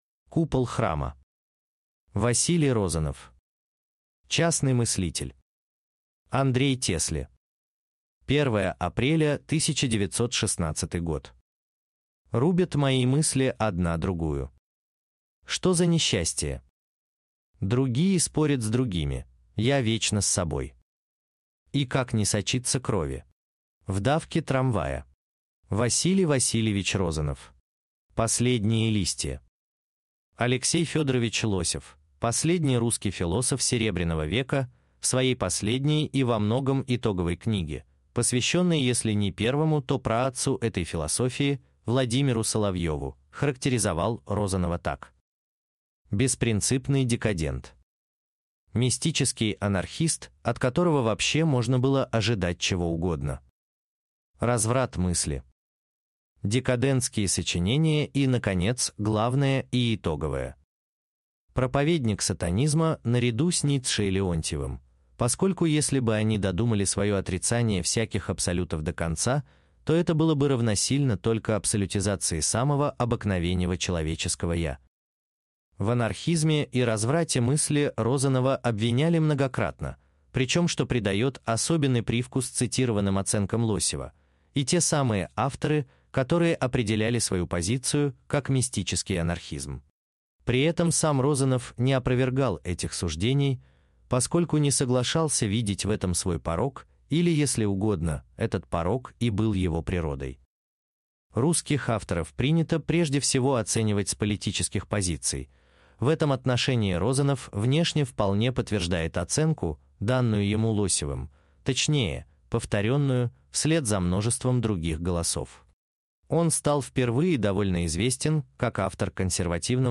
Аудиокнига В темных религиозных лучах. Купол храма | Библиотека аудиокниг